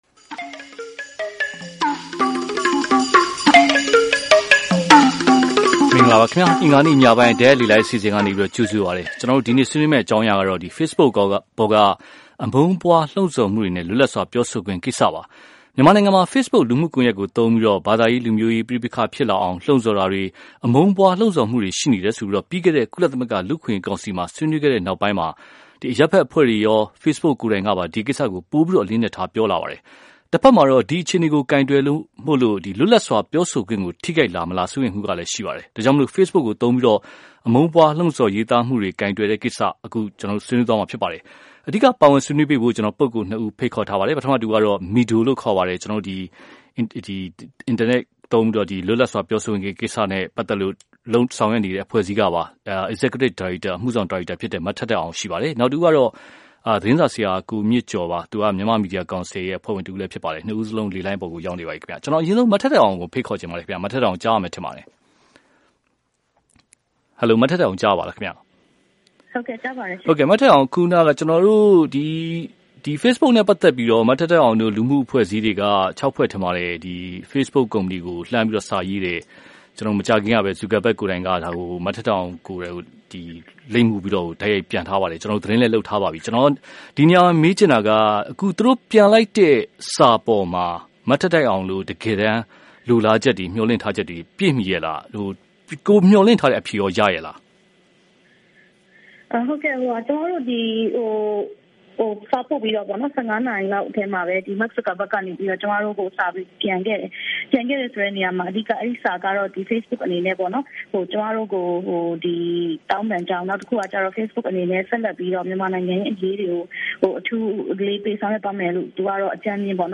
ဖေ့ဘုတ်ပေါ်အမုန်းပွားလှုံဆော်မှုတွေ ဘယ်လိုကိုင်တွယ်မလဲ (တိုက်ရိုက်လေလှိုင်း)